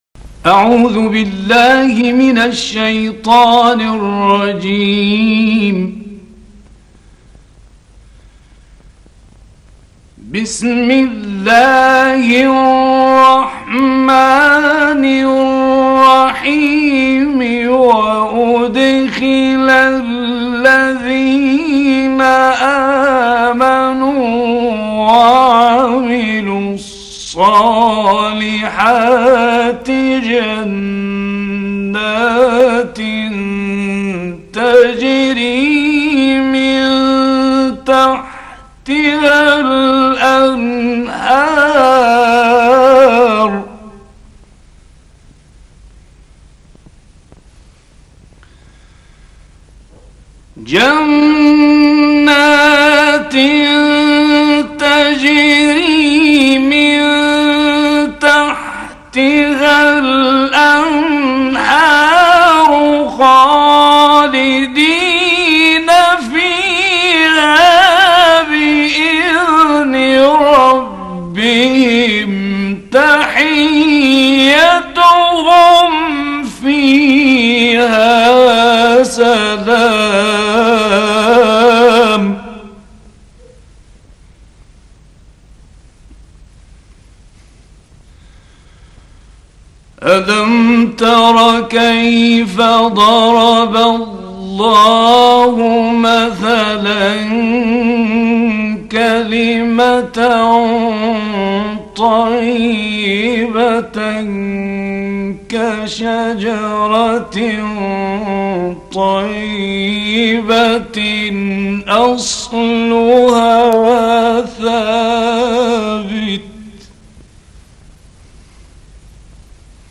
گروه فعالیت‌های قرآنی: مقاطع صوتی با صدای قاریان ممتاز کشور مصر را می‌شنوید.
مقطعی از سوره ابراهیم با صوت راغب مصطفی غلوش